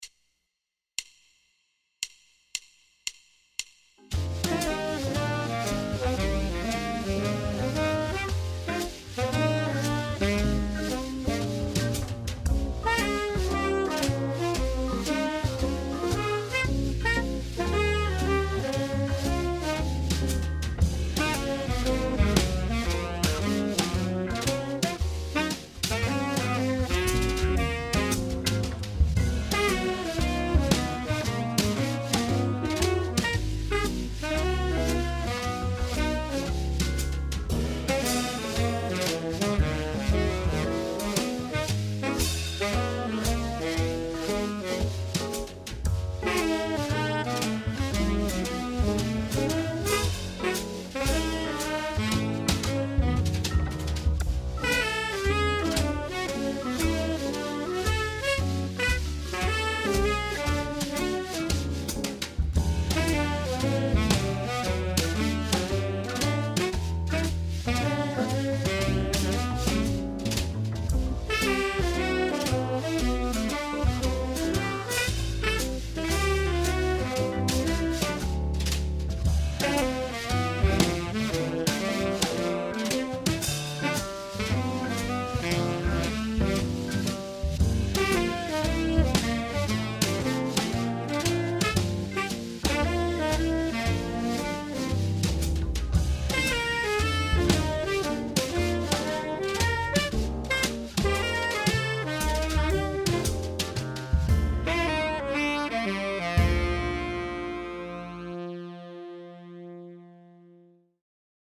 Below that, you’ll find a recording and chart of the phrase in all 12 keys, in case you’d like to woodshed.
the-lick-in-all-12-keys.mp3